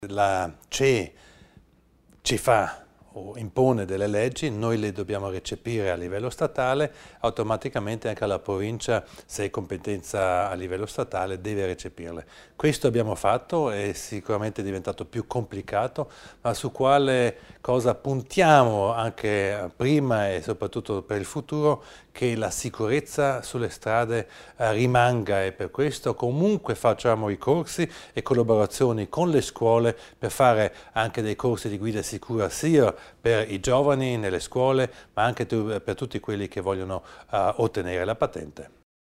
L'Assessore Widmann spiega l'impegno a favore della sicurezza sulle strade